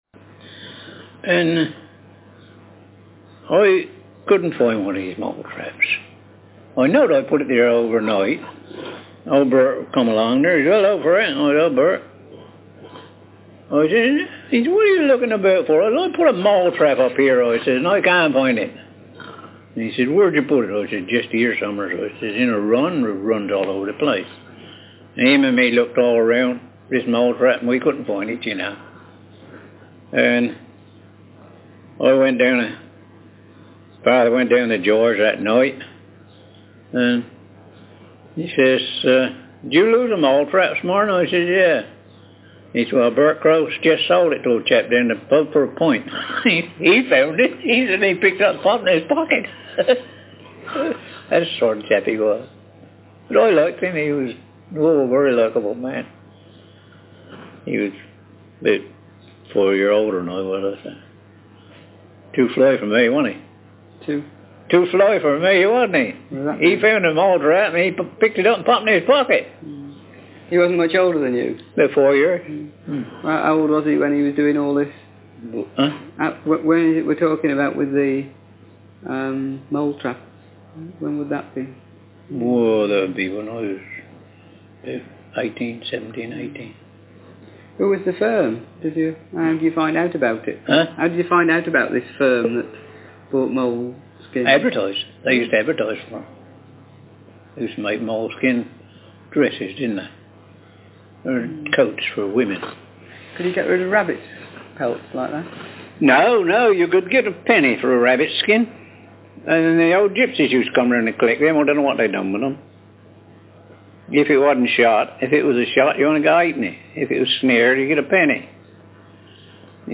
DoReCo - Language English (Southern England)
Speaker sex m Text genre personal narrative